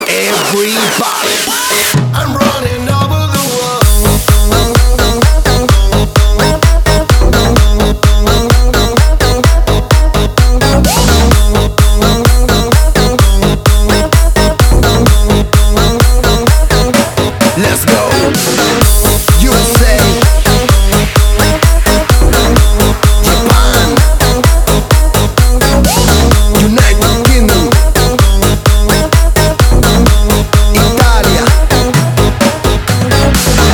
• Качество: 320, Stereo
Стиль: Club House